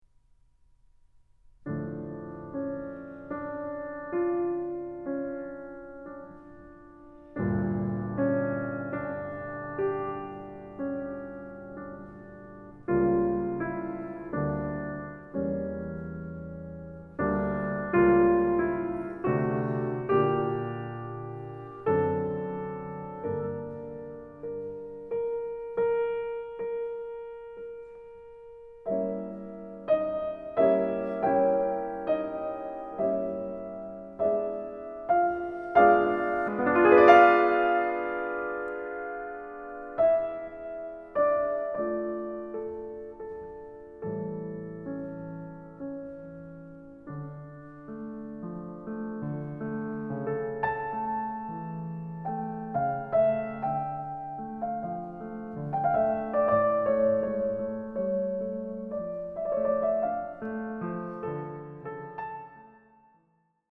Beethoven gives the pianist the instruction that it is Largo e mesto, which means broad (slow) and sad.
Which brings us to play the opening of the Op. 10 No. 3 slow movement, and here we have Beethoven’s first ever slow movement in minor of the Piano Sonatas: